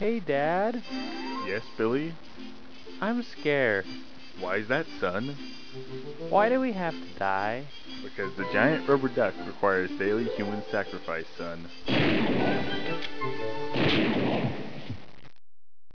duck.wav